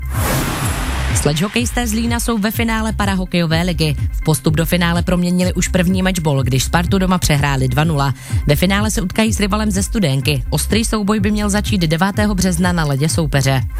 Reportáž po 2. semifinálovém utkání ČPHL 2018/2019